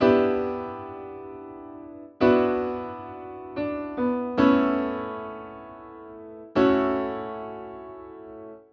Audio 1.7 - Piano